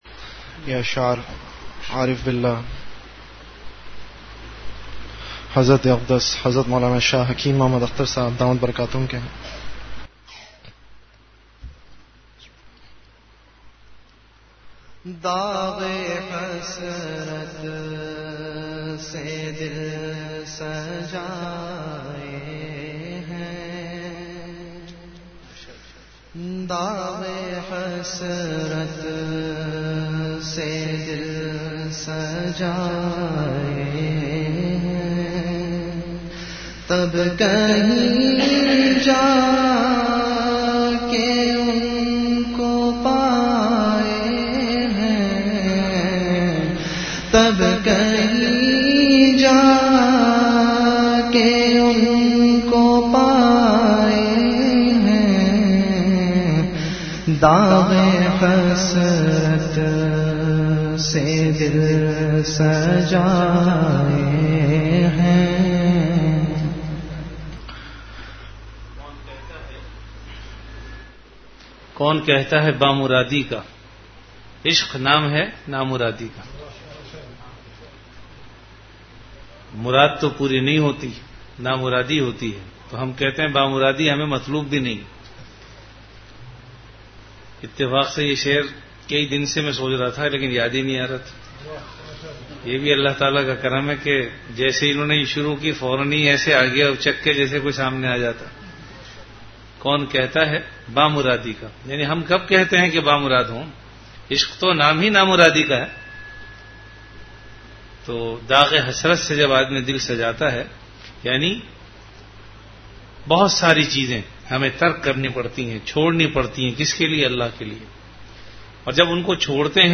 Delivered at Home.
Majlis-e-Zikr · Home Khoone Hasrat